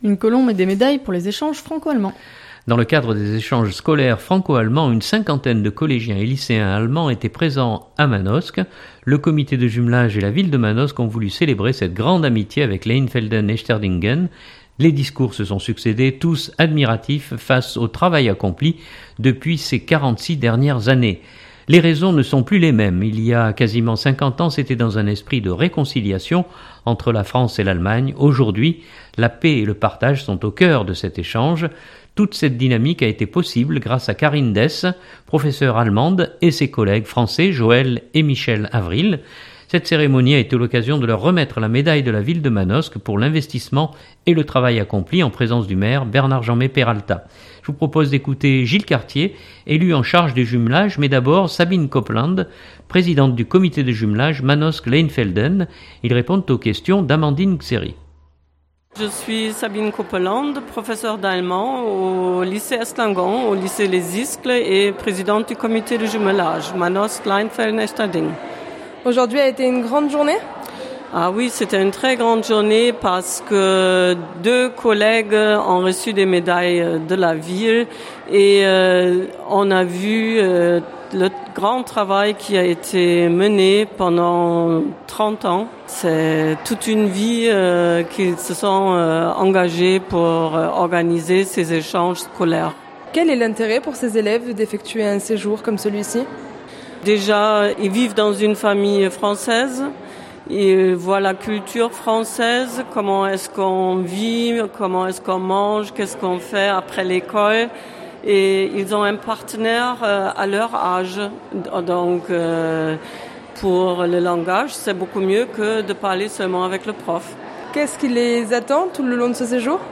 Journal du 2017-10-17 - Manosque - Jumelage.mp3